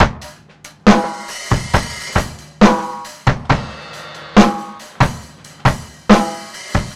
C BEAT 1  -R.wav